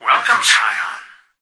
"Welcome scion" excerpt of the reversed speech found in the Halo 3 Terminals.